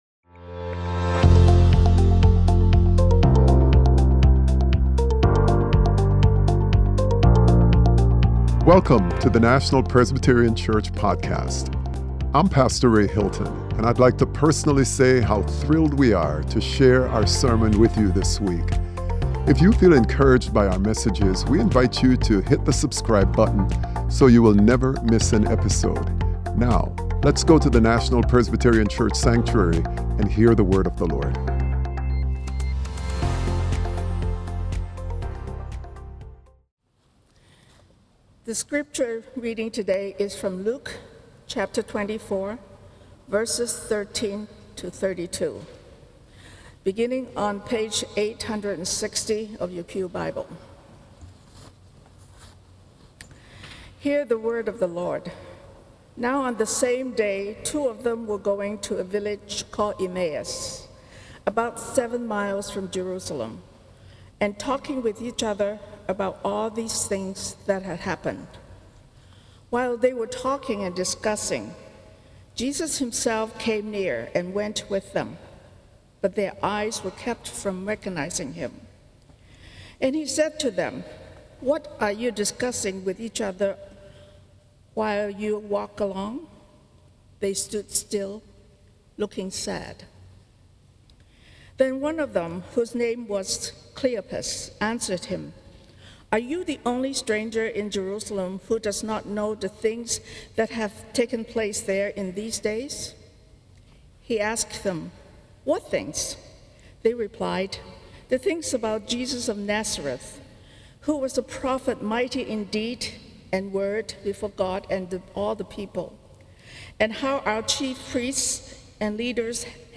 Sermon: Encountering God More Deeply - Encountering God in Scripture - National Presbyterian Church